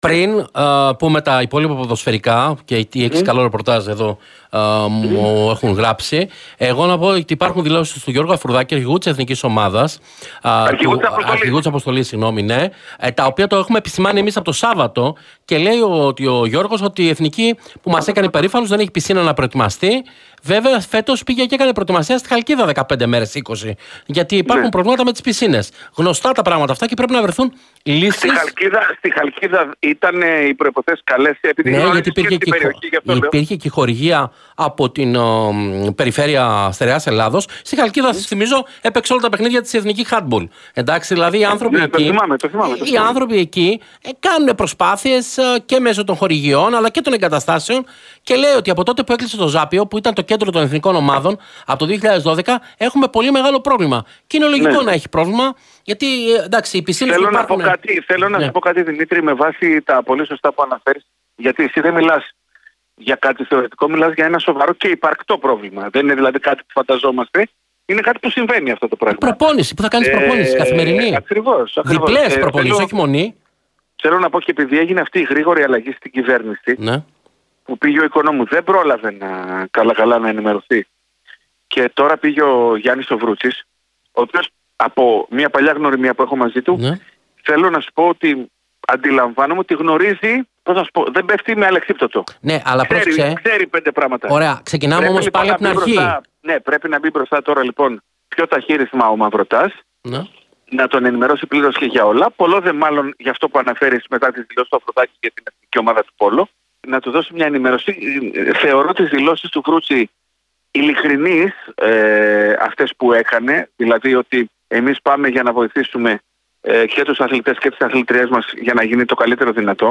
στην εκπομπή Real sports